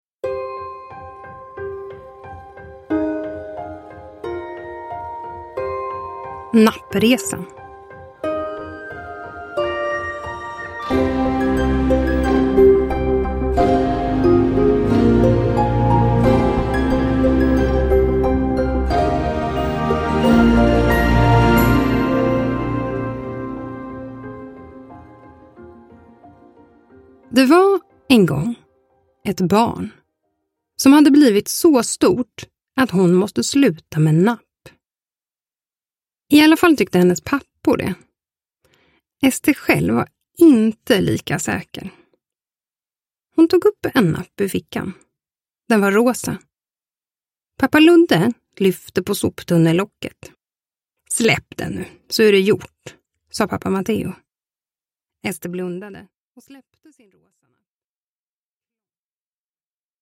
Nappresan : eller när Ester slutade med napp – Ljudbok – Laddas ner